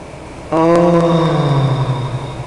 Ohhhh! (echo) Sound Effect Download
Ohhhh! (echo) Sound Effect
ohhhh-echo.mp3